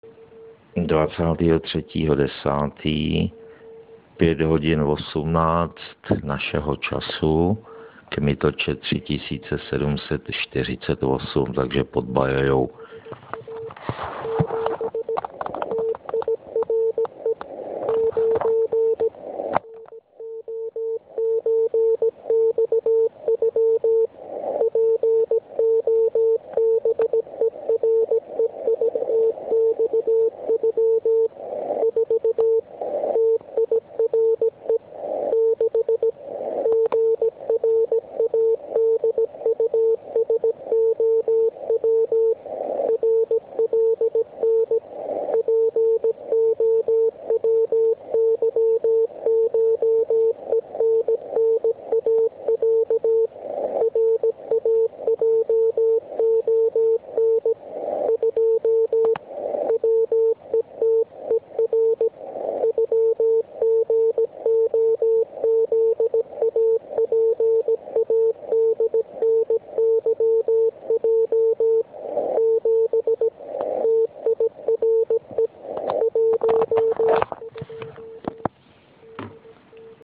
Dnes jsem poslouchal zajímavou CW stanici RMP ve spojení s ROE.
Proto jsem je zachytil na 3748 KHz.